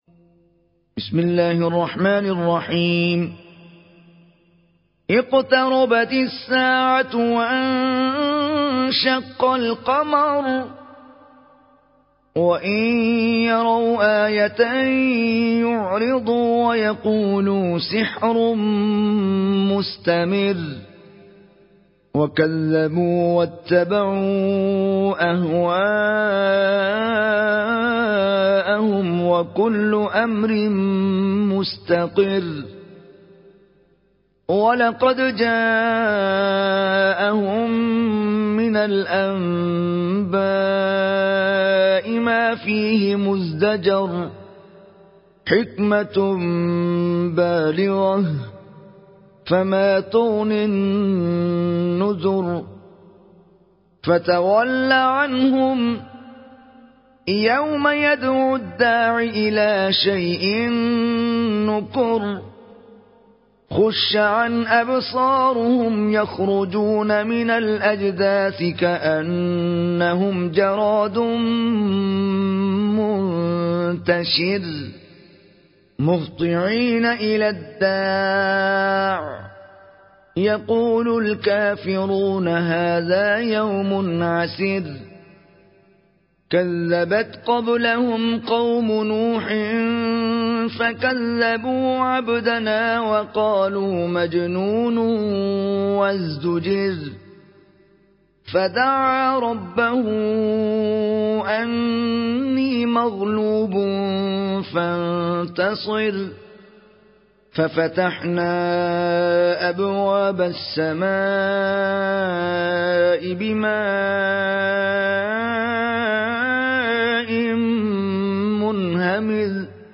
Complete Quran Recitation